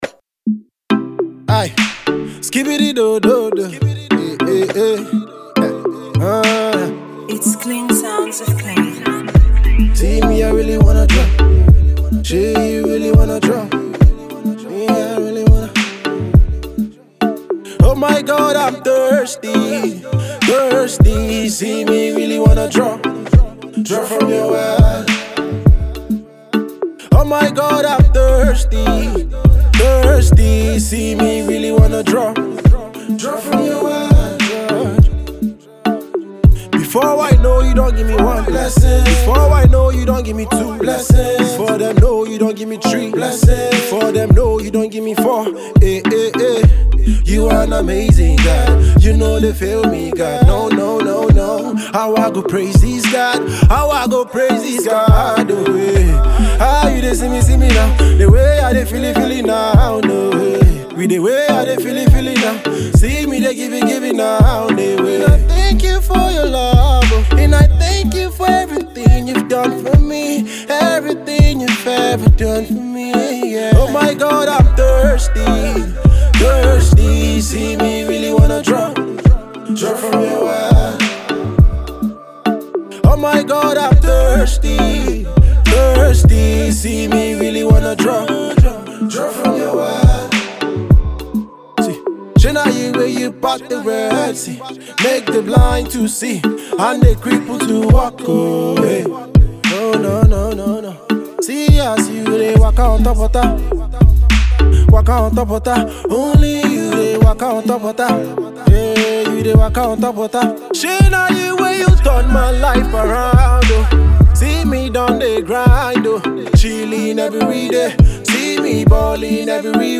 Christian Afro-pop